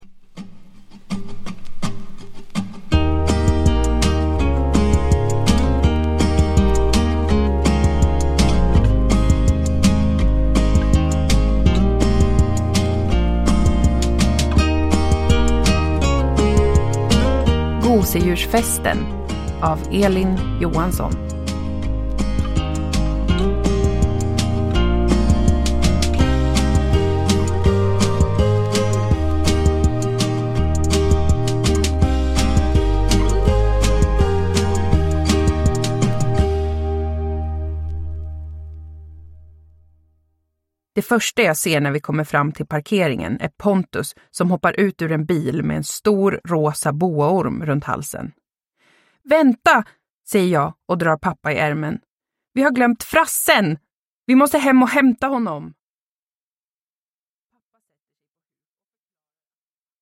Gosedjursfesten – Ljudbok – Laddas ner